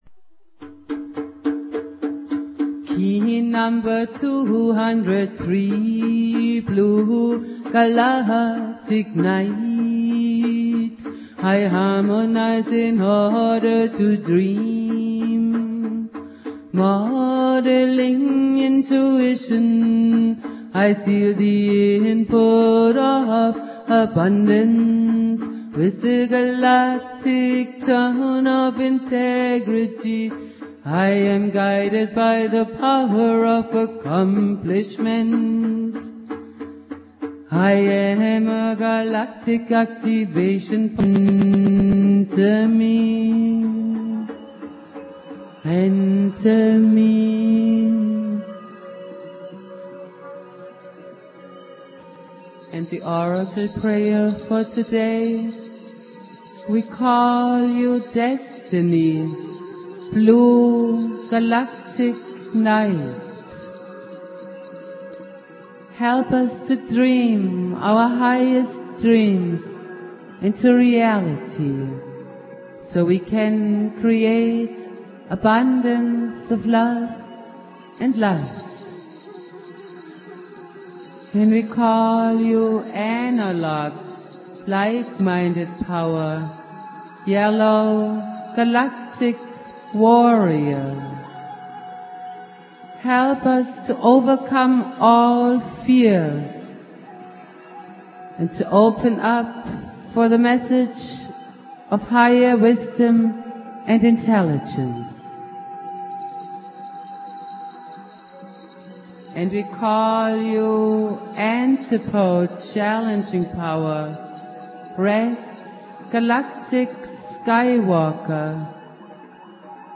Prayer
Jose's spirit and teachings go on Jose Argüelles playing flute.